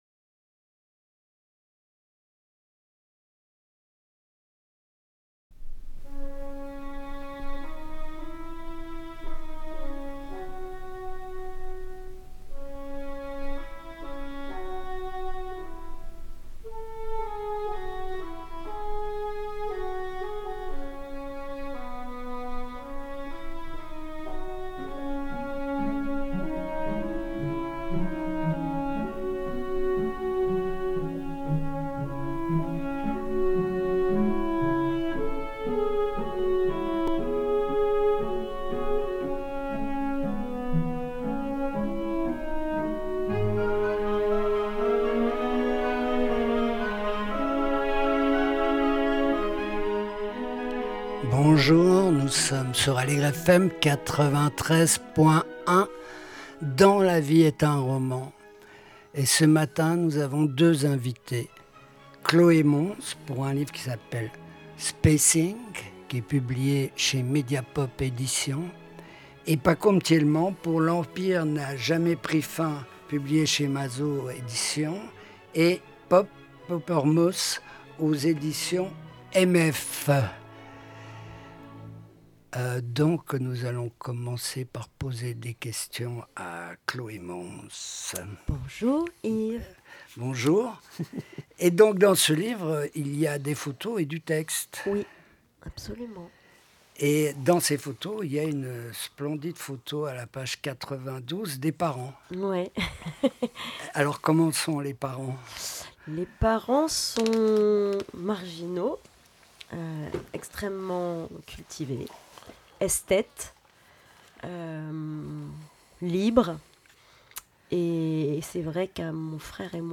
Entretiens avec Chloé Mons et Pacôme Thiellement